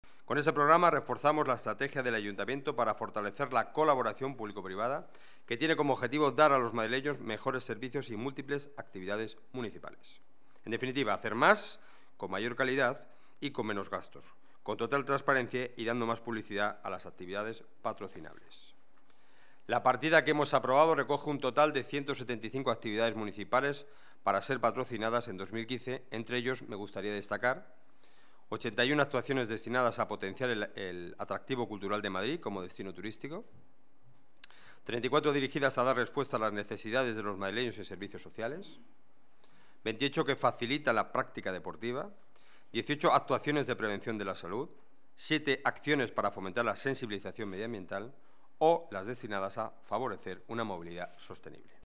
Nueva ventana:Declaraciones portavoz Gobierno municipal, Enrique Núñez: Junta Gobierno, aprobación Plan Patrocinios 2015